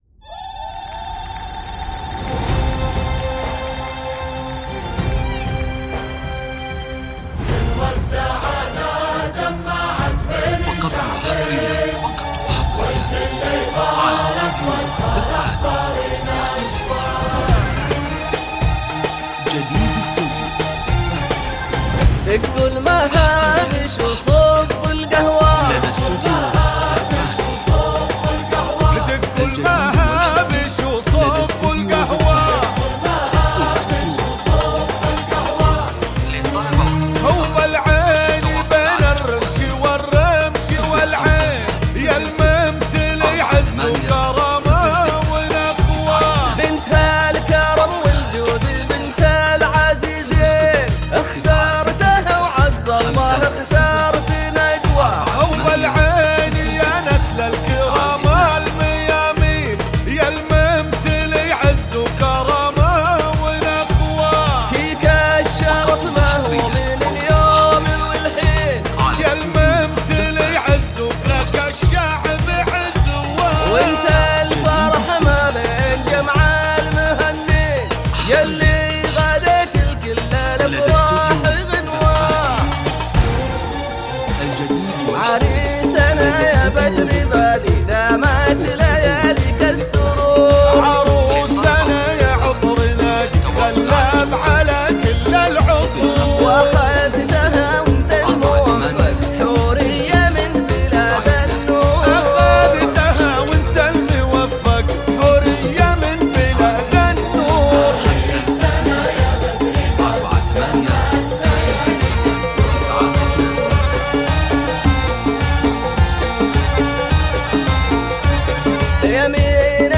زفه معرس